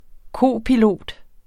Udtale [ ˈkoˀpiˌloˀd ]